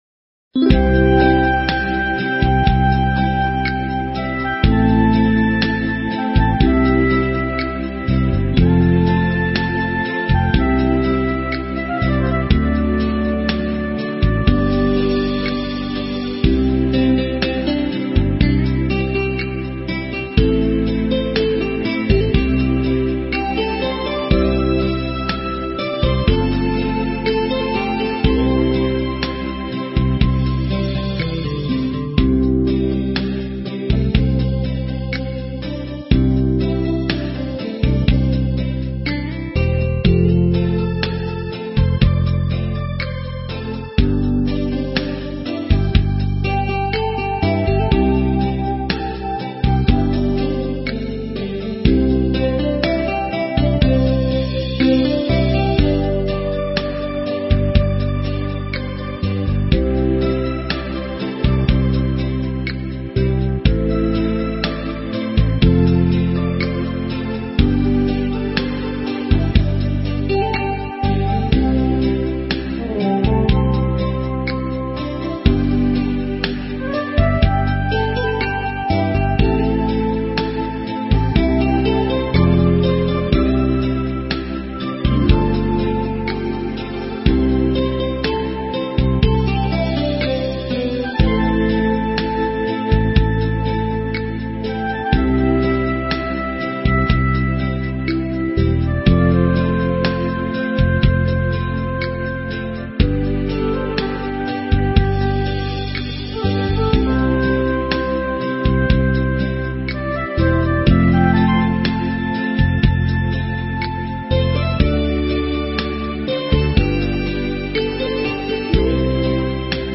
※링크를 클릭하시면 반주곡 미리듣기를 할수 있어요.